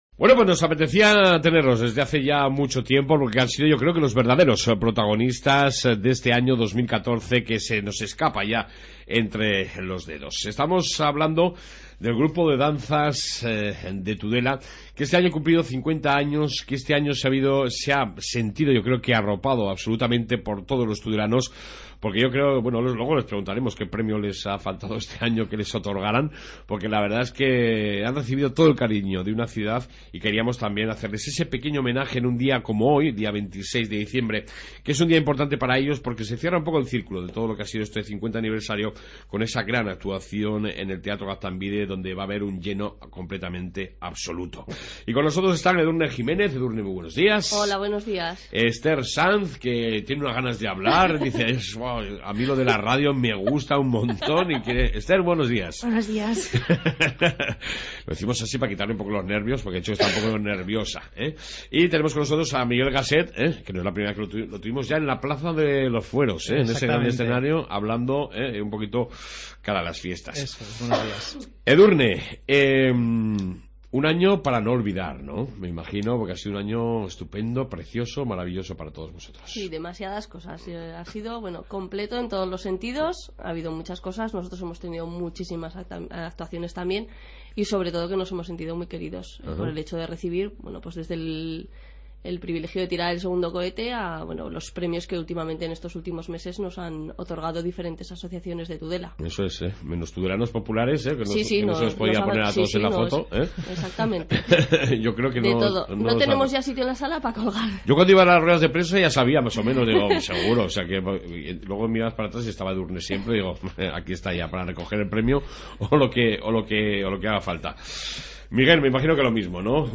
AUDIO: Amplia entrevista con parte fel grupo de danzas de Tudela en su 50 aniversario...